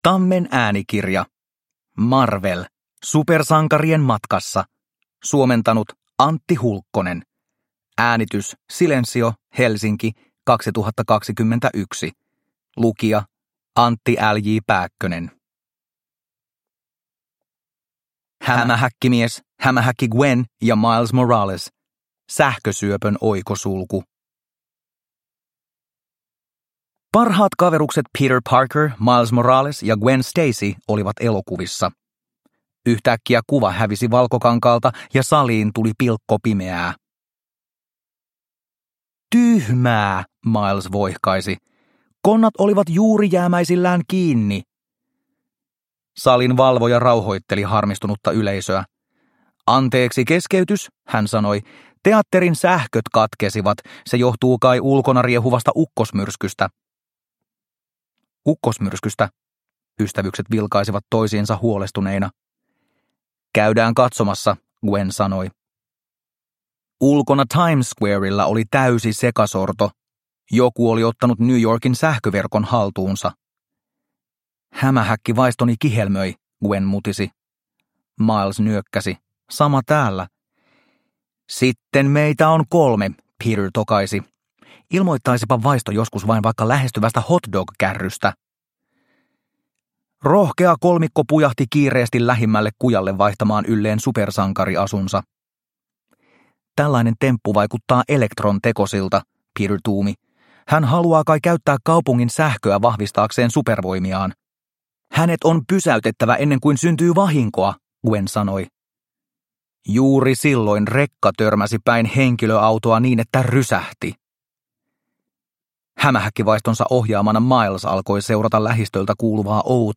Marvel. Supersankarien matkassa – Ljudbok – Laddas ner